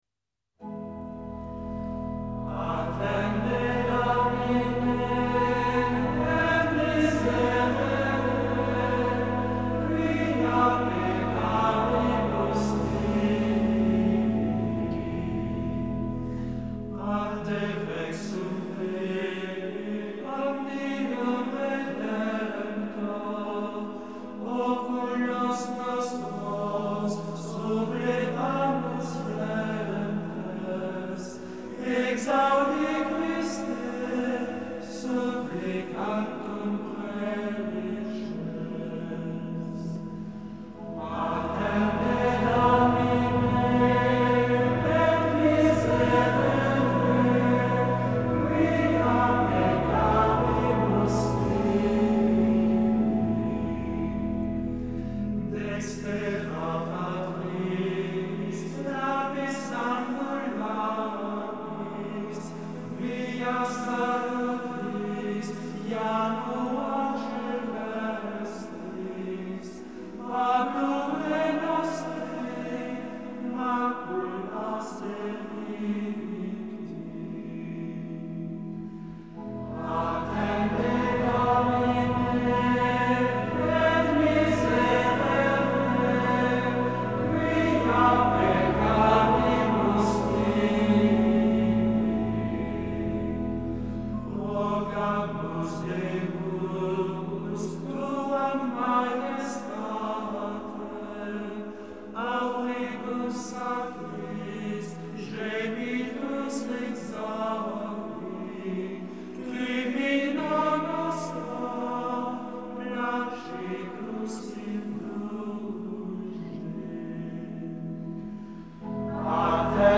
CHANTS GRÉGORIENS